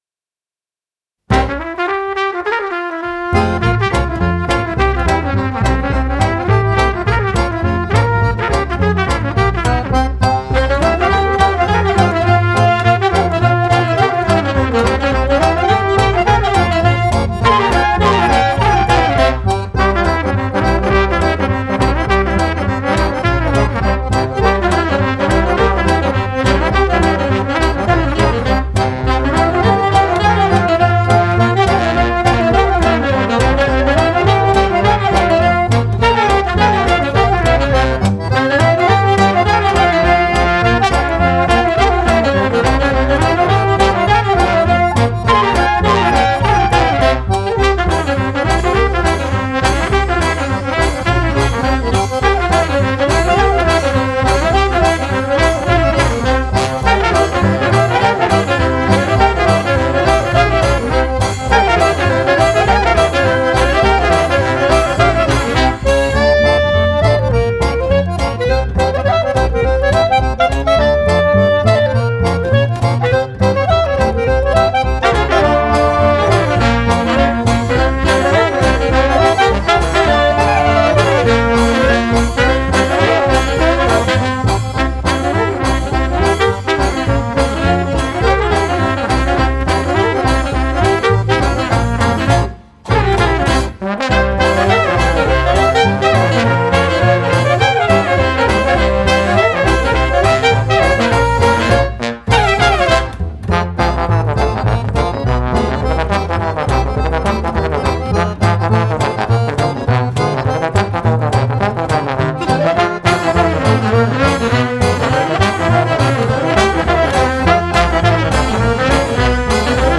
Genre: Klezmer, Folk, World